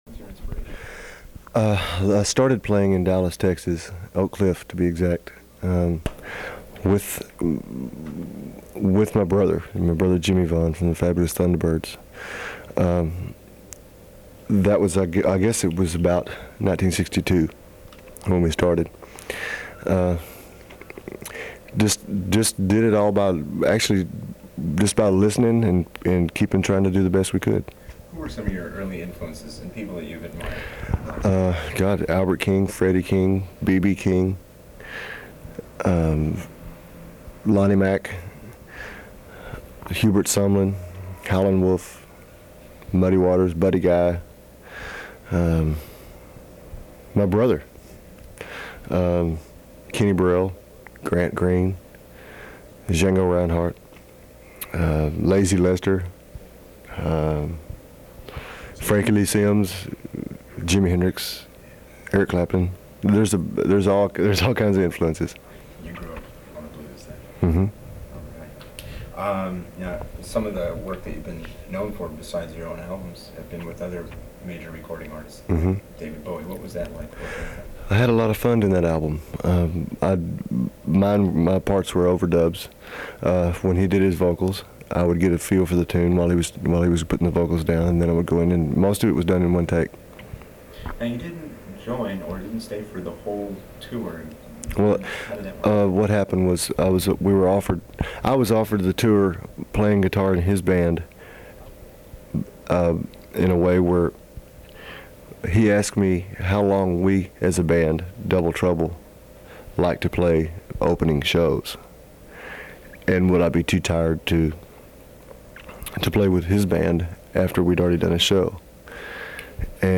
But this interview was hopeful and the future was looking bright – and Stevie Ray Vaughan was clear and articulate and passionate about his music.
It wasn’t meant to be broadcast, so there are some blips and bumps and the interviewers questions are muddled.